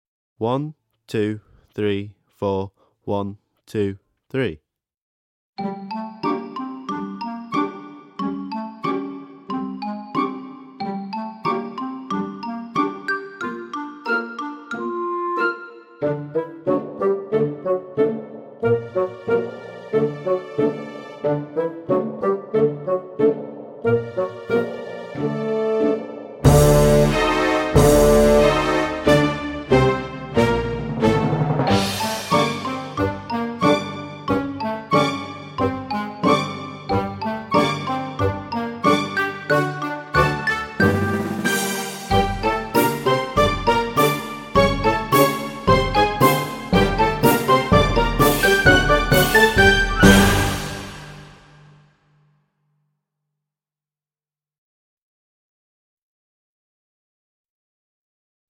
VS Trolls, Orcs and Goblins (backing track)